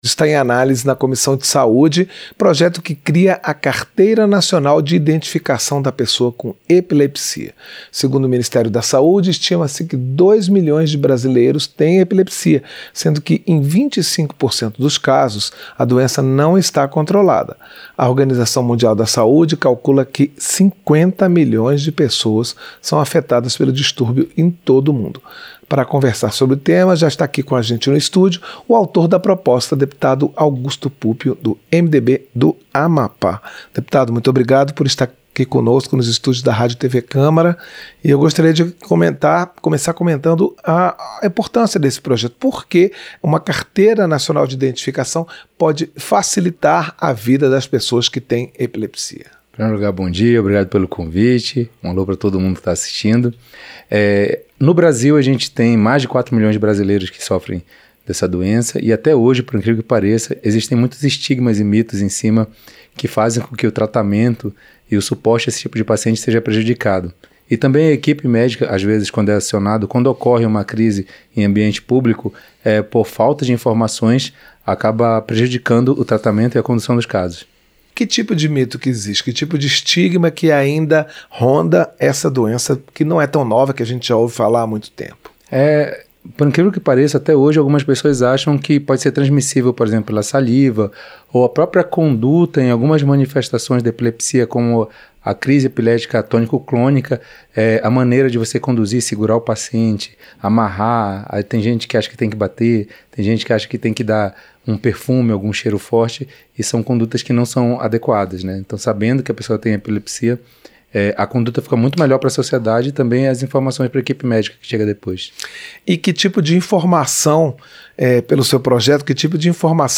• Entrevista - Dep. Augusto Puppio (MDB-AP)